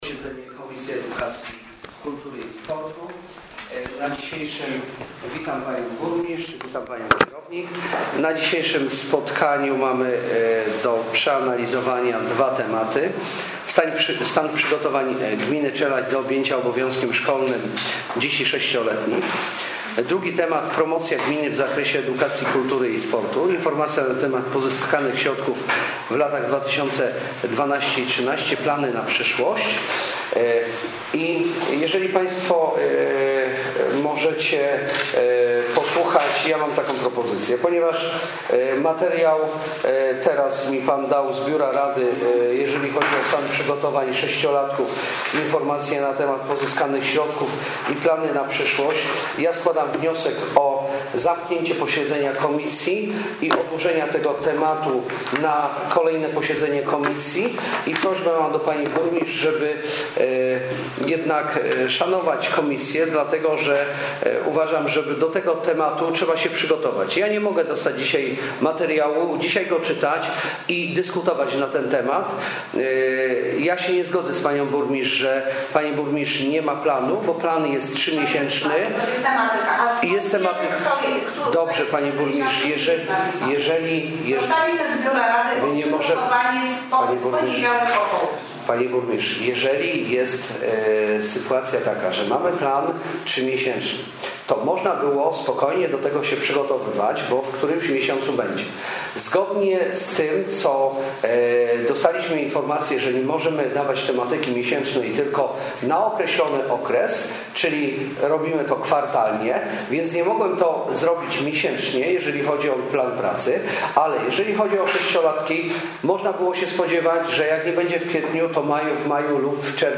Nagranie z posiedzenia komisji doraźnej Edukacji Kultury i Sportu w dniu 12 maja 2014 r.   MP3 8,056.6 KB  2015-01-30